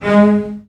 CELLOS.A#3 S.wav